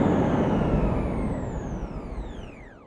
autoPilotStop.wav